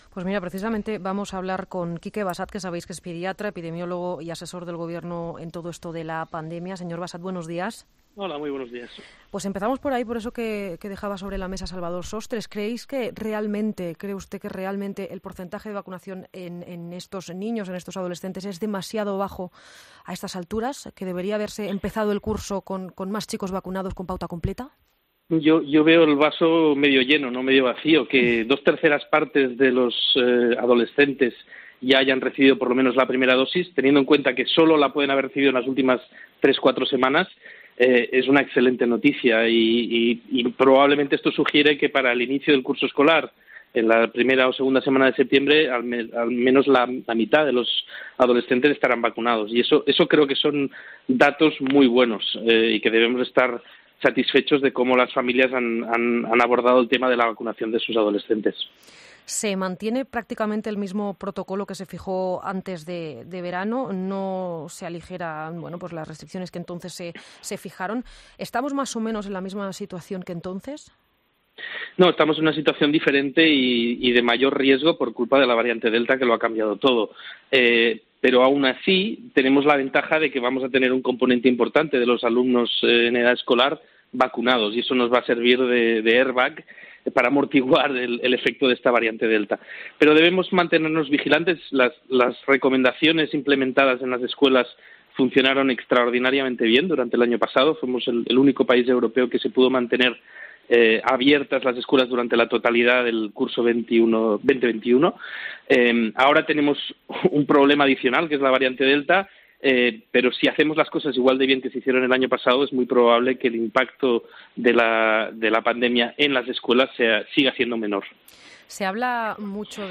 Epidemiólogo, en COPE: “No tengo evidencia para apoyar el uso generalizado de la tercera dosis"